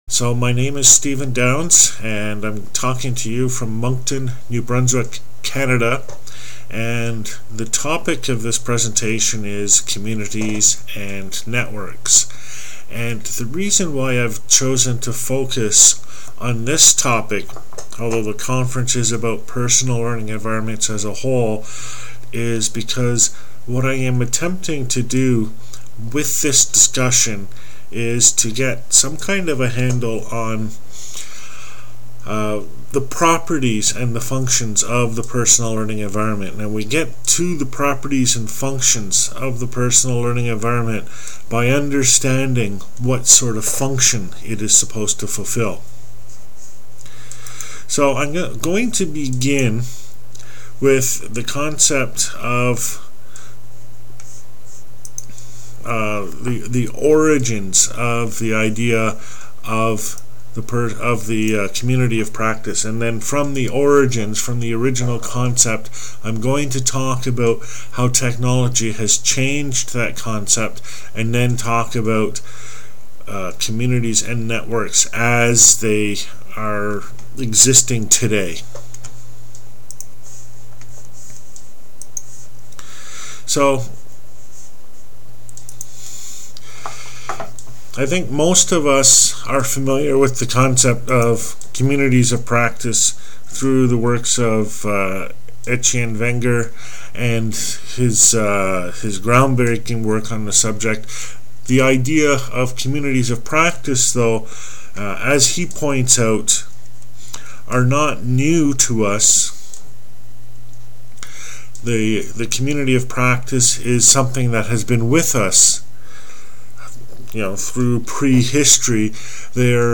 Presentation on the concept of the community of practice, tracing how it evolved from its original form resembling groups and featuring shared objectives and meanings, to one resembling networks, being composed more of interactions and conversations. Some technical difficulties (edited out of the video) truncated the presentation, unfortunately.